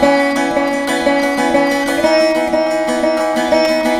119  VEENA.wav